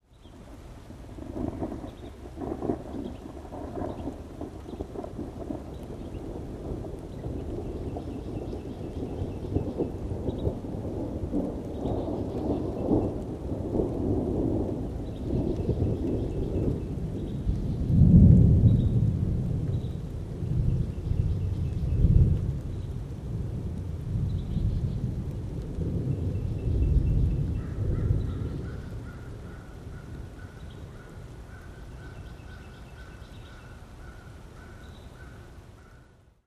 am_rain_thunder_03_hpx
Thunder rumbles over light rain as birds chirp in background. Rain, Thunderstorm Storm Weather, Thunderstorm